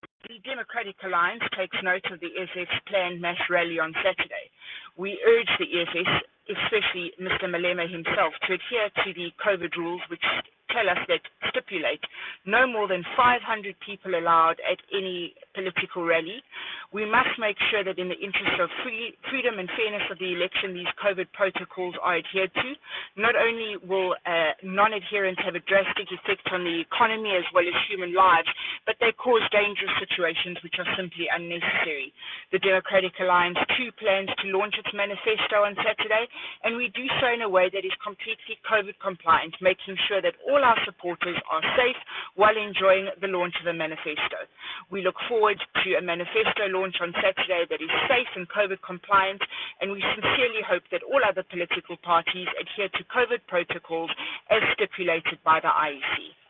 soundbite by Natasha Mazzone MP.